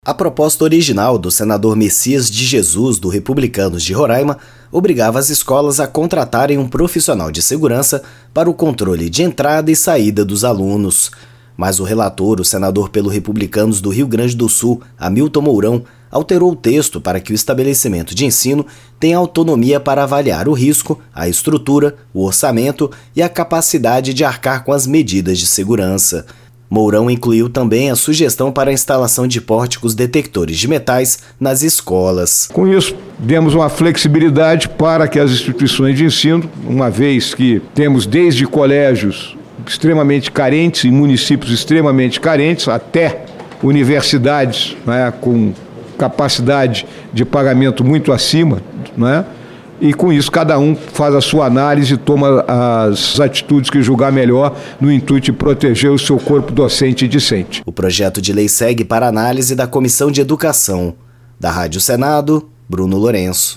O relator, Hamilton Mourão (Republicanos-RS), diz que cada escola vai avaliar a necessidade ou não de adotar as medidas.